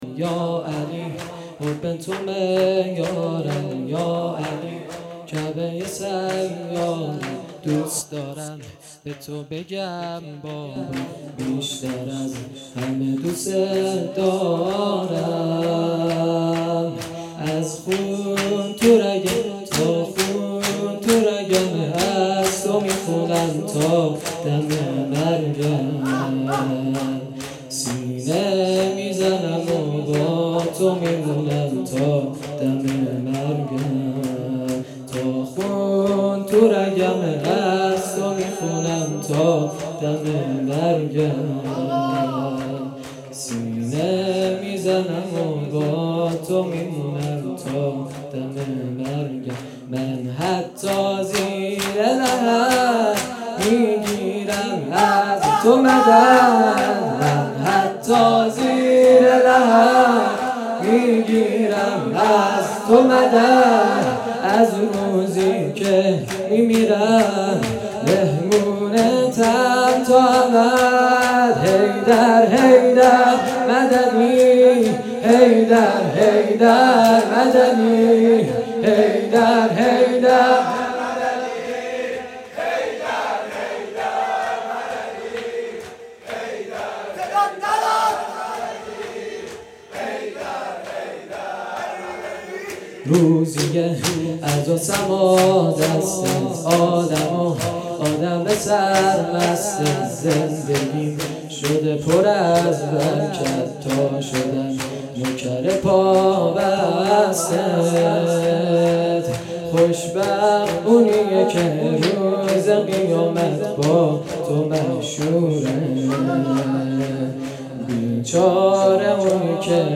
یا علی حب تو معیارم - شور